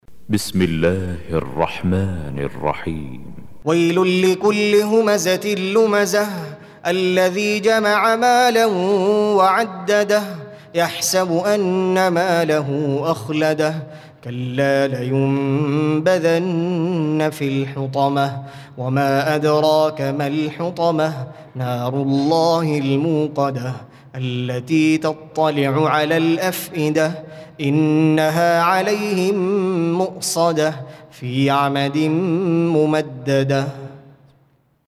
Surah Repeating تكرار السورة Download Surah حمّل السورة Reciting Murattalah Audio for 104. Surah Al-Humazah سورة الهمزة N.B *Surah Includes Al-Basmalah Reciters Sequents تتابع التلاوات Reciters Repeats تكرار التلاوات